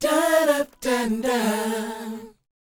DOWOP E 4B.wav